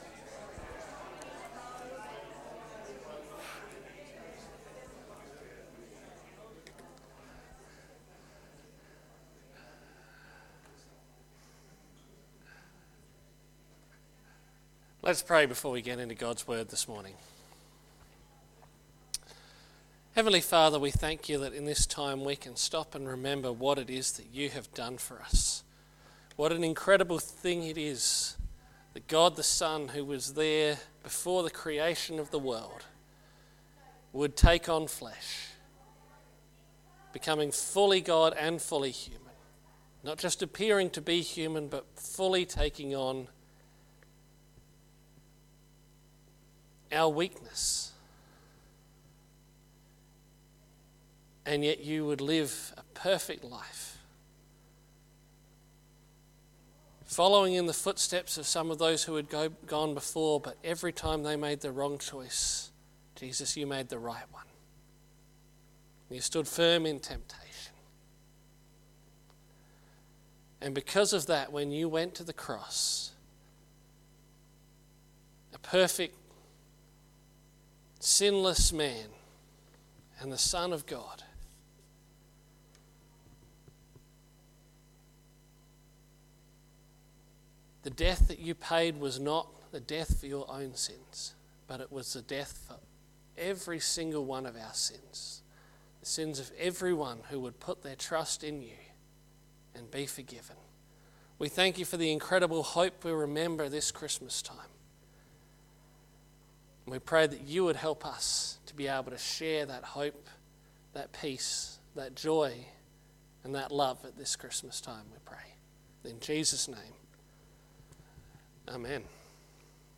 Sermons by Birdwood United Church